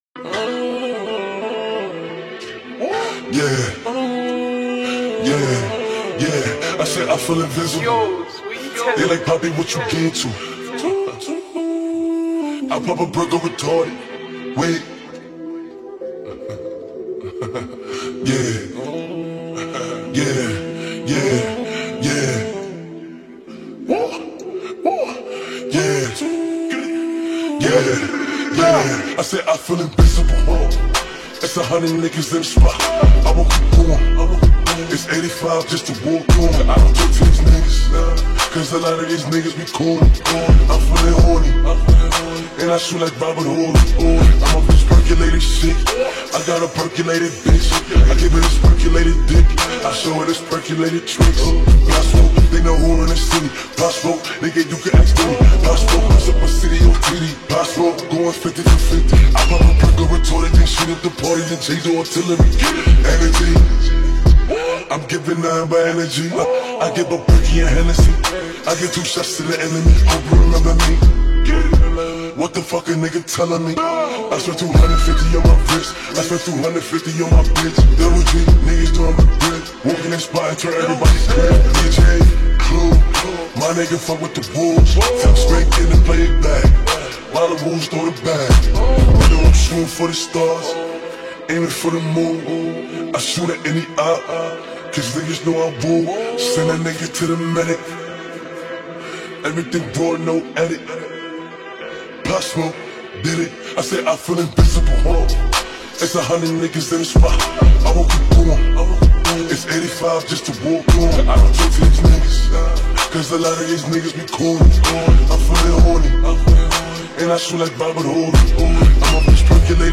ورژن آهسته کاهش سرعت